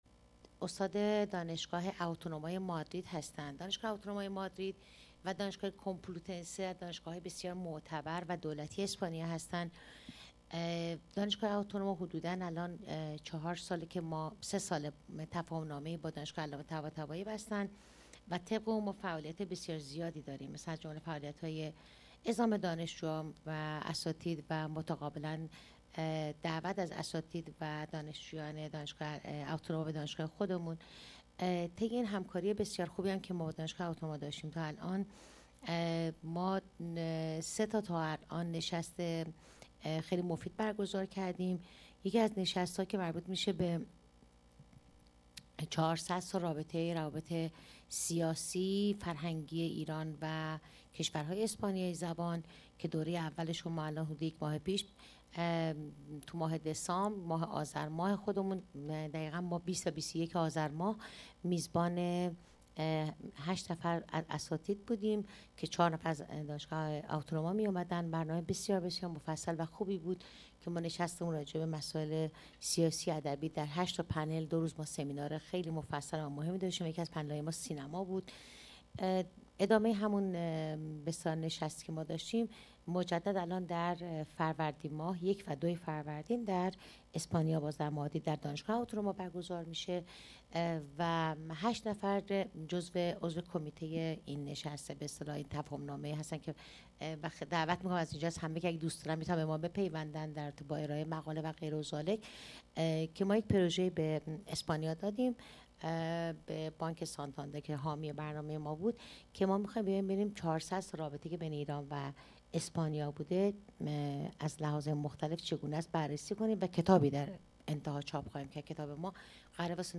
عنوان سخنرانی برخورد ایدئولوژیکی اسلام و مسیحیت در قرون وسطی Ideological Confrontation between Islam and Christianity duringthe middel ages
سالن حکمت